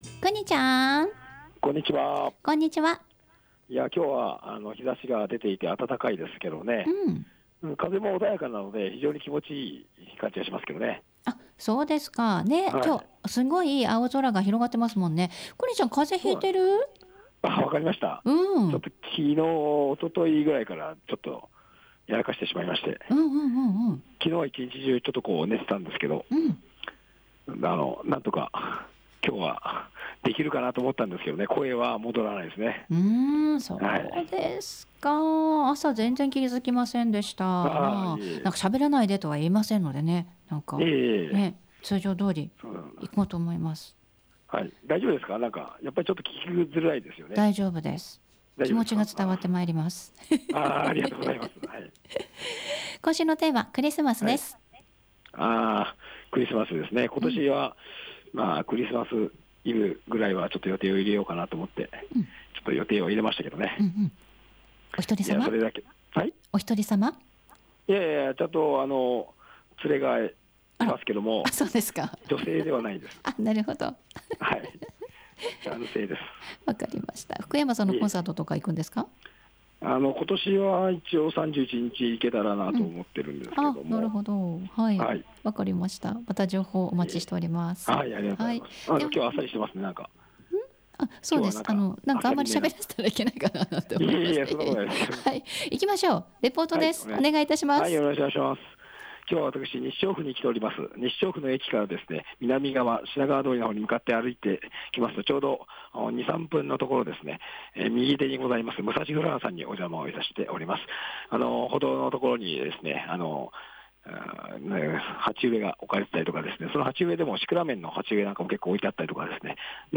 午後のカフェテラス 街角レポート
風邪を引きまして お聞き苦しい点があったことお詫びいたします。